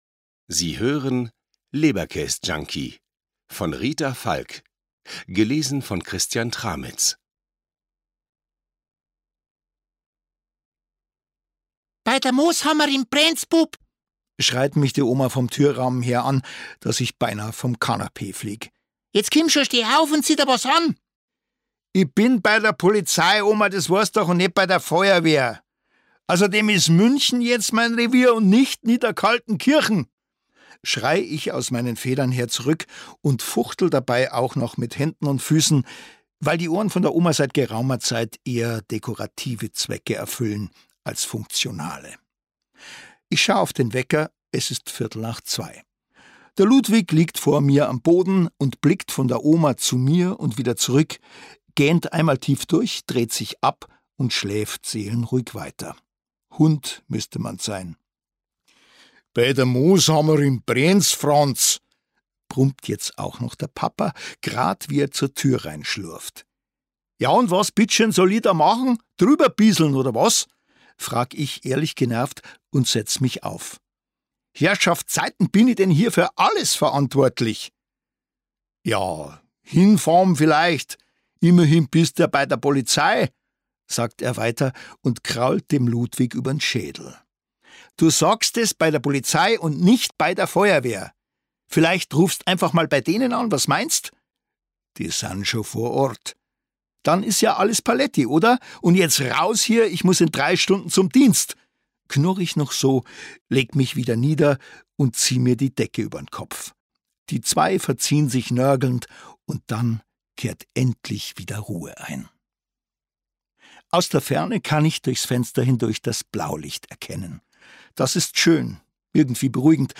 Die große Franz-Eberhofer-Box 3 Ungekürzte Lesungen mit Christian Tramitz
Christian Tramitz (Sprecher)